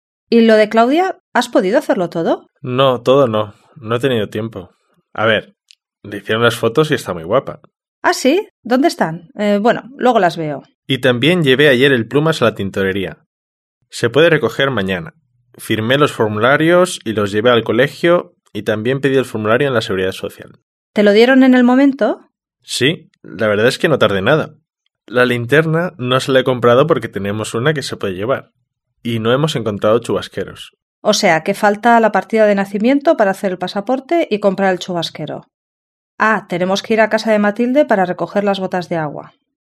4. Escuche ahora otra conversación entre Rosa y Emilio. ¿Qué hizo Emilio durante la ausencia de Rosa?